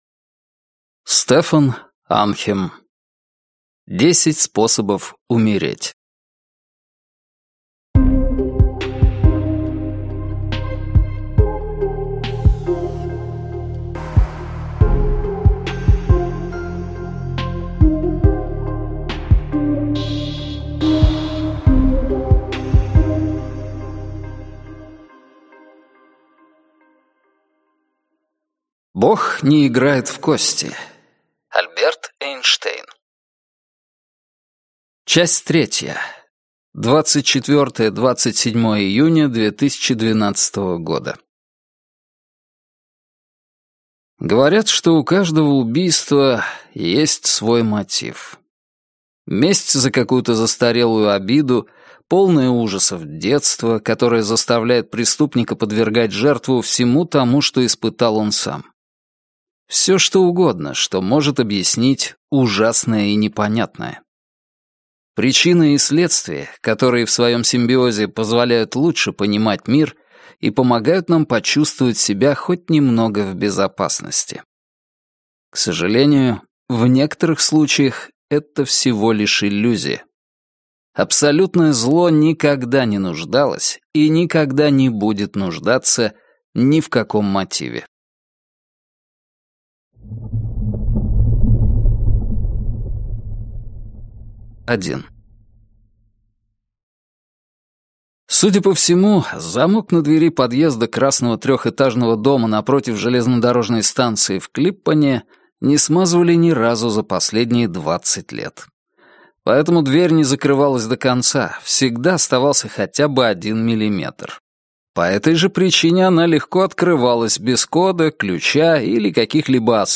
Аудиокнига 10 способов умереть | Библиотека аудиокниг